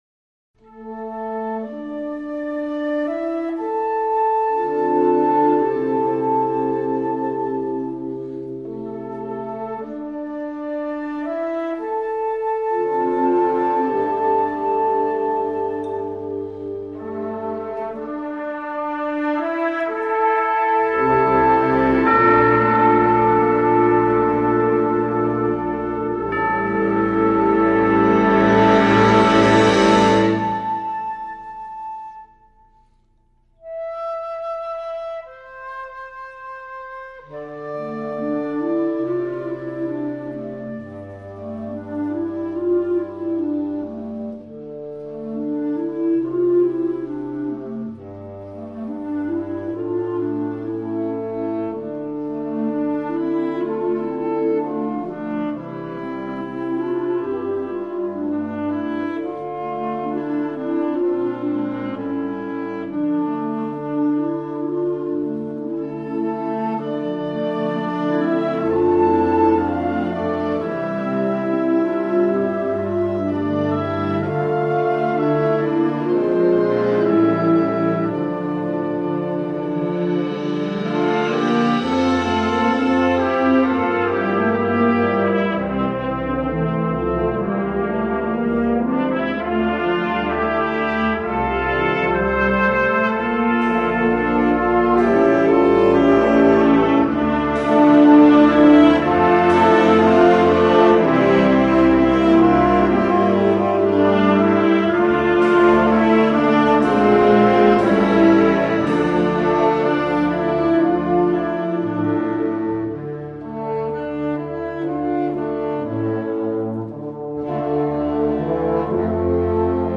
Instrumental Concert Band Concert/Contest
Concert Band